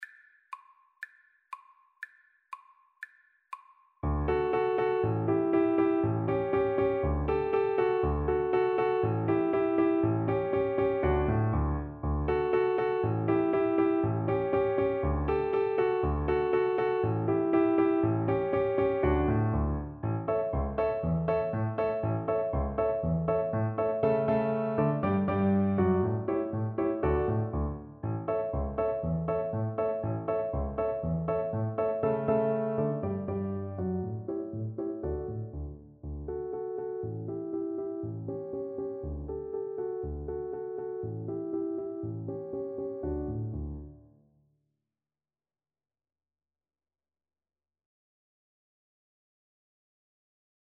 Allegro (View more music marked Allegro)
2/4 (View more 2/4 Music)
Classical (View more Classical Cello Music)